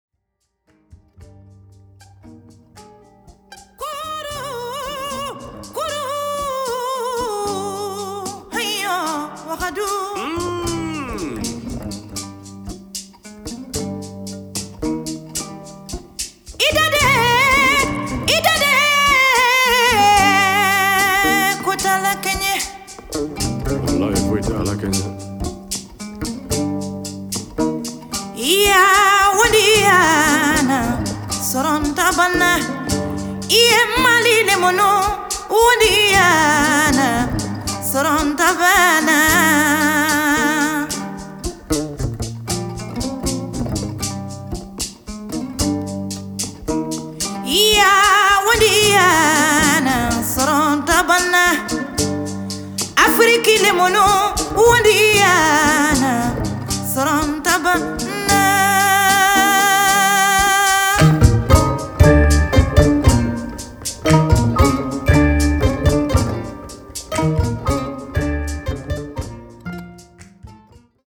ngoni (lute) virtuoso